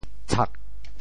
潮州发音 潮州 cag4
tshak4.mp3